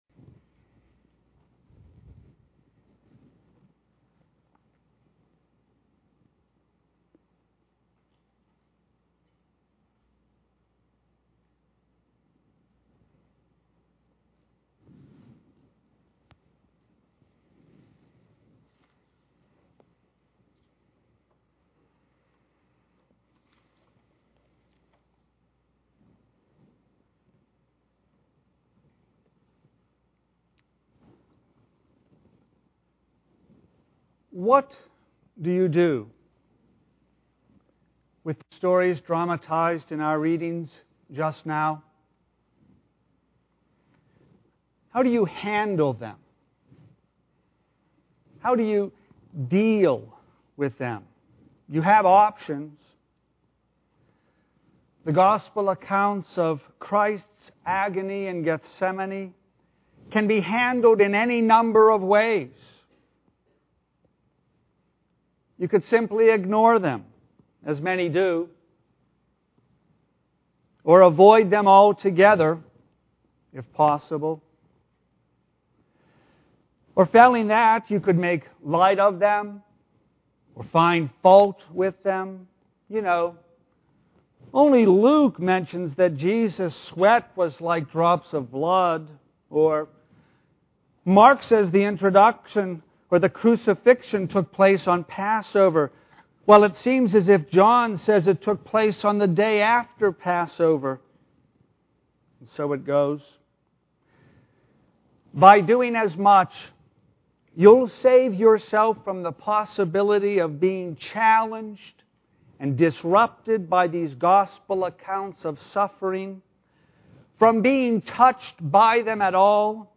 sermon-audio-3.29.13.mp3